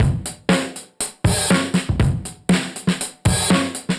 Index of /musicradar/dusty-funk-samples/Beats/120bpm/Alt Sound